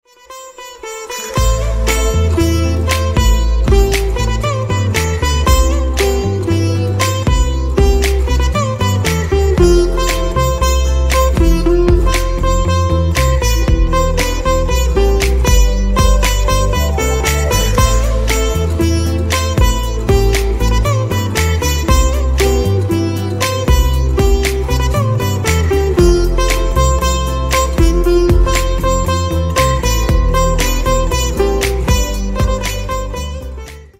Pop & Rock
Sitar Cover Sitar